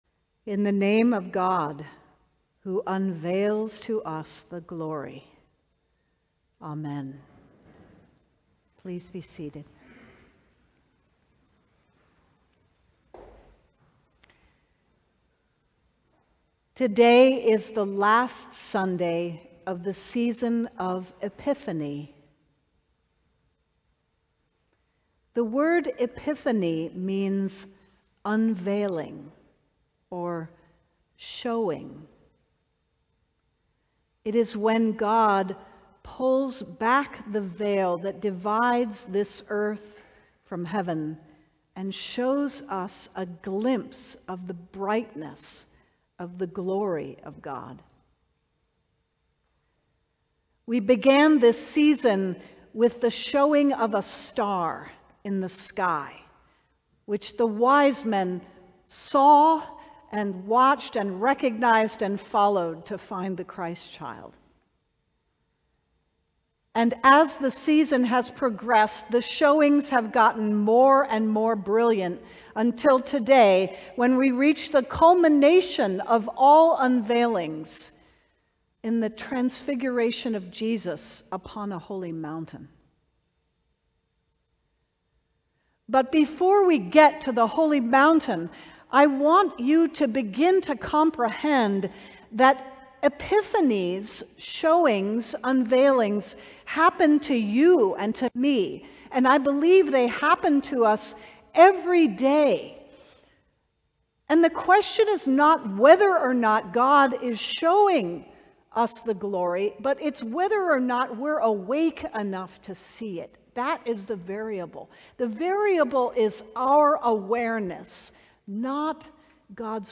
Sermon: Doxa